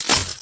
Barrier sound added.
barrier.wav